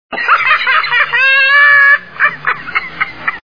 Laugh.wav
Dudley Moore laughing.
laugh.wav